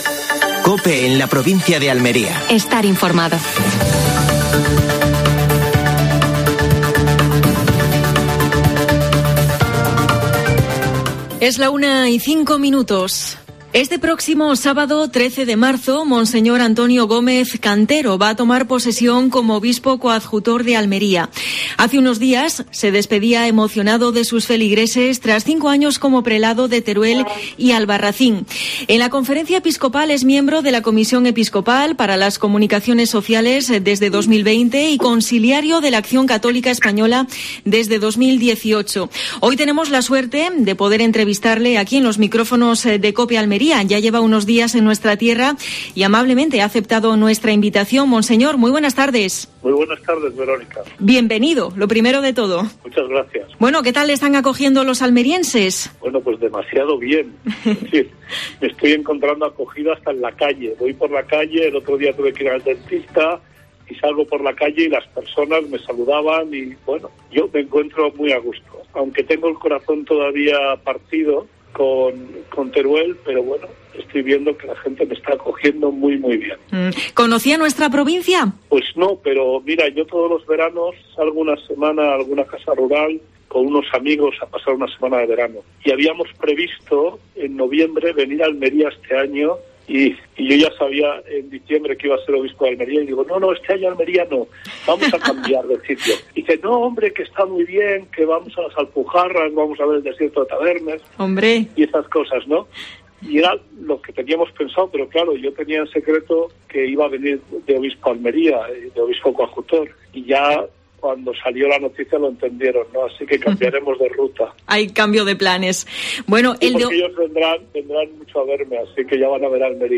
Entrevista al nuevo Obispo coadjutor de Almería (Mons. Antonio Gómez Cantero).